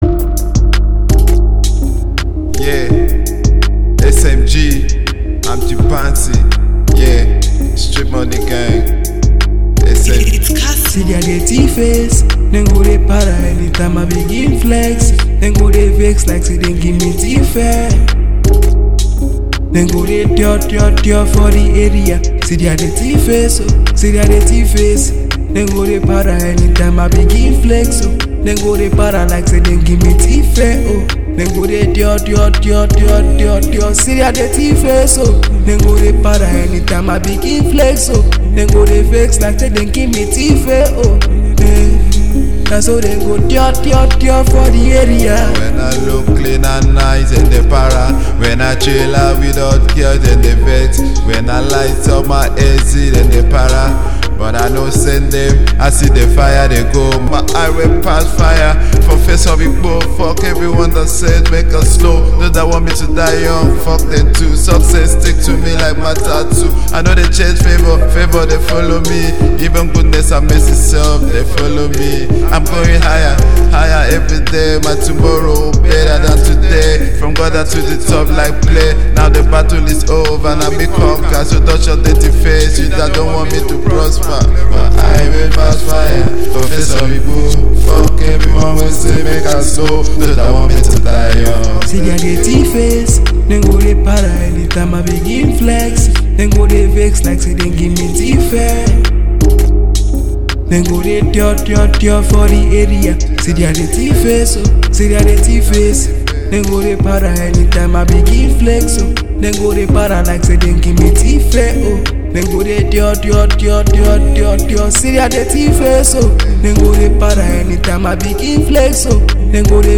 A song writer and rapper